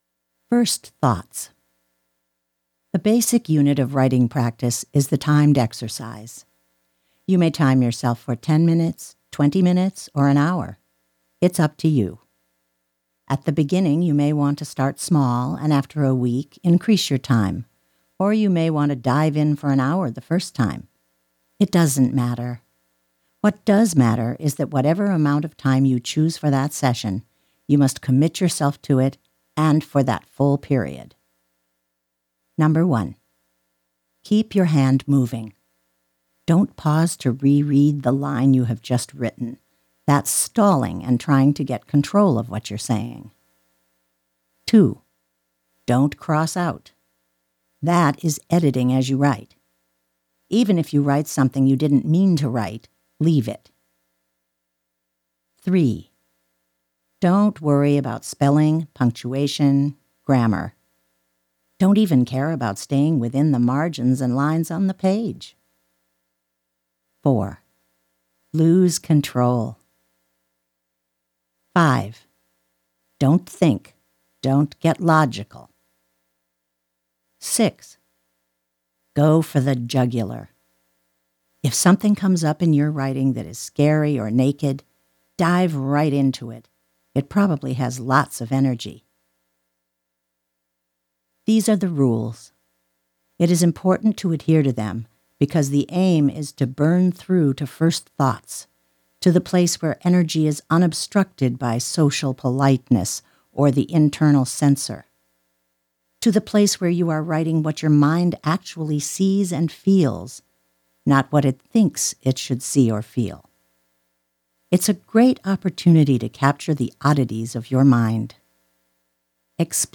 Inviting, earthy and wise.
Audiobook: Writing Down The Bones
American English